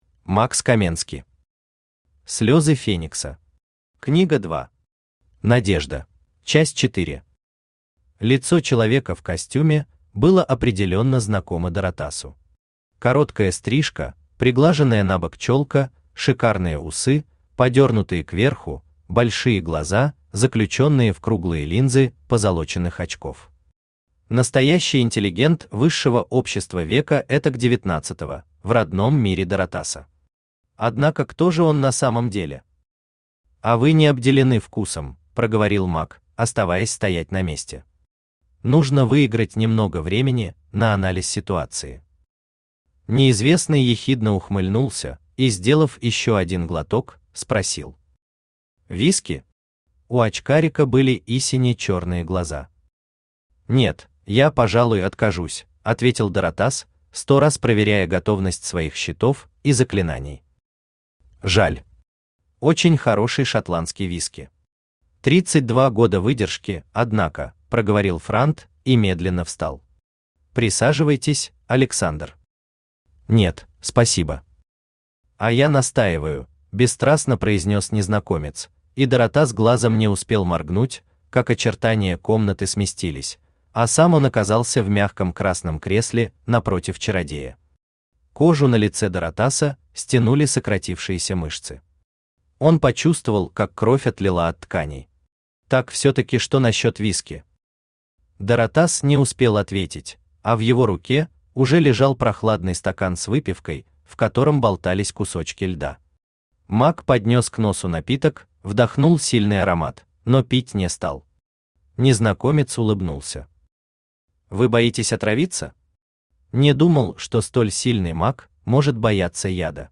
Aудиокнига Слезы Феникса. Книга 2. Надежда Автор Макс Каменски Читает аудиокнигу Авточтец ЛитРес.